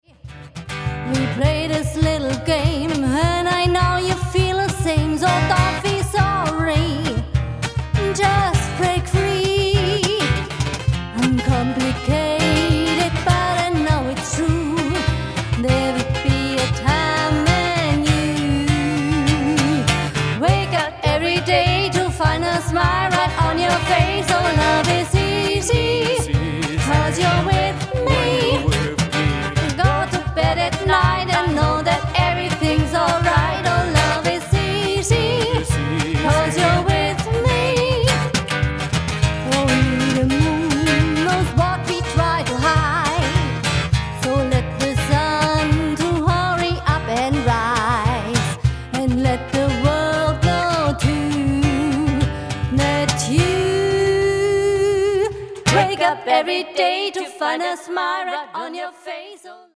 unplugged Gitarren Pop